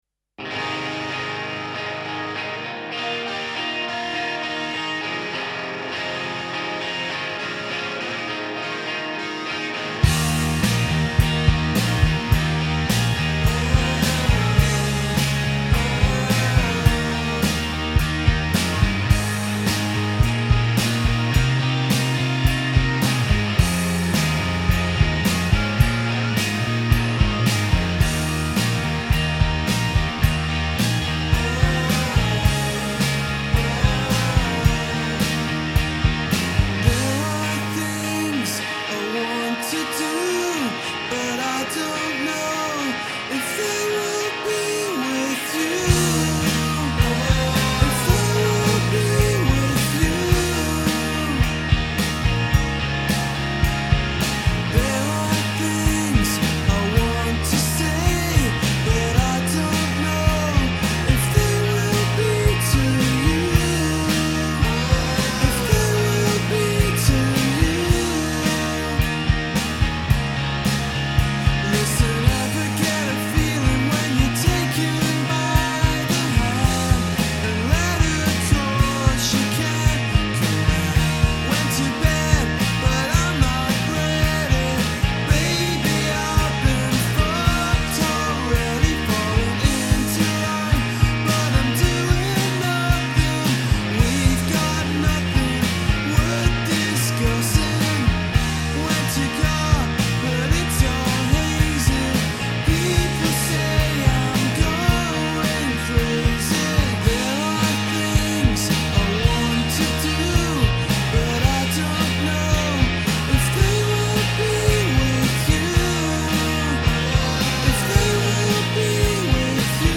fantastically louche
gloriously ragged live take
clanging chords
world-weary, 30-a-day Marlboro-coated voice